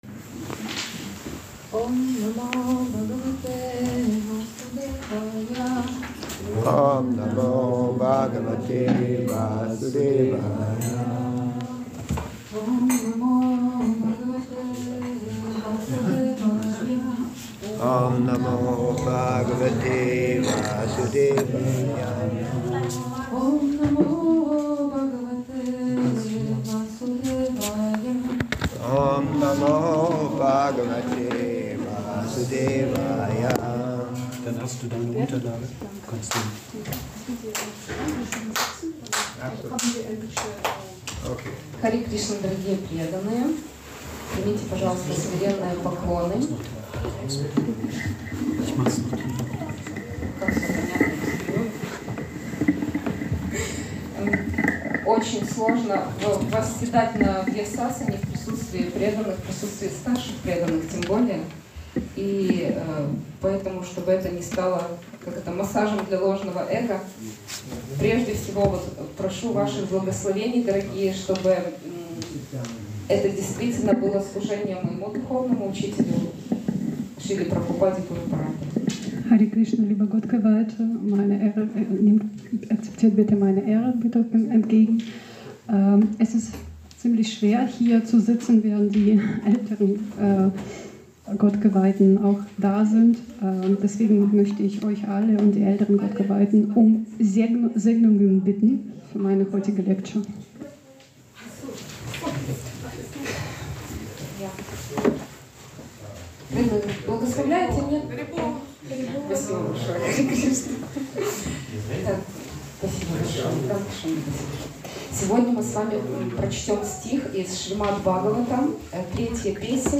Vorträge im Bhakti Yoga Zentrum Hamburg